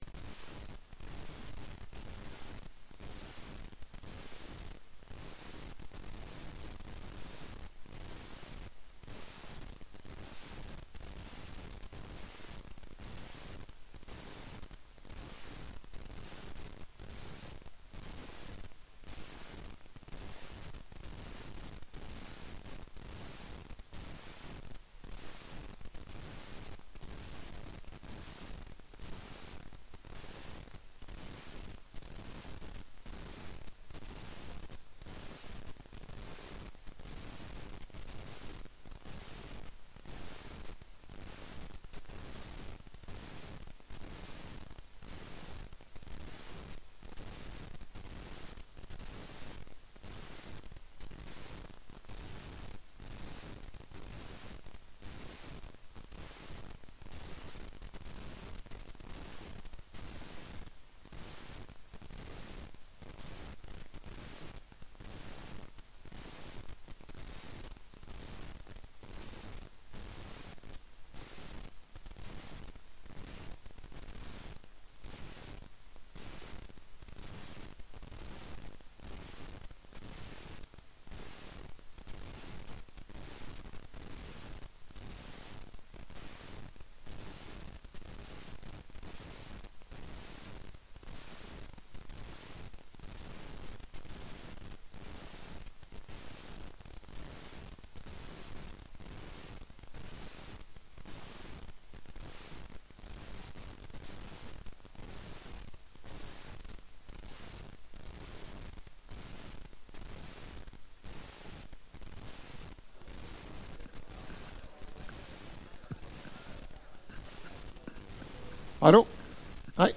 Kjemiforelesning 7
Rom: Store Eureka, 2/3 Eureka